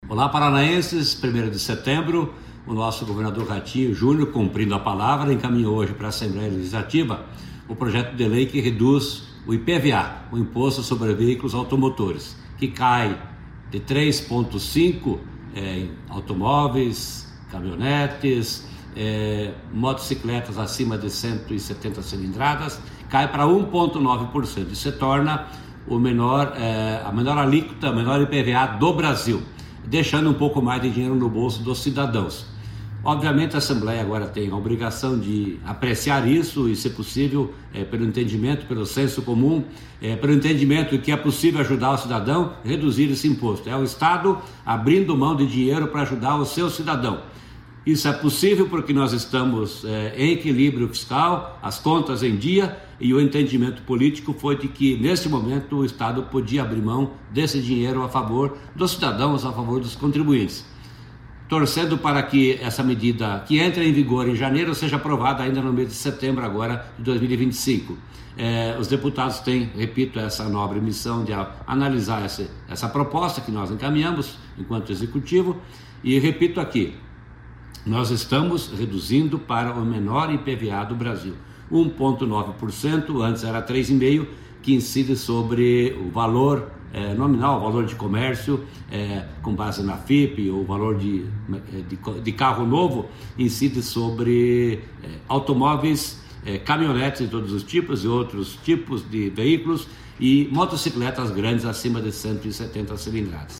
Sonora do secretário da Fazenda, Norberto Ortigara, sobre o envio do projeto de lei para tornar IPVA do Paraná o mais barato do Brasil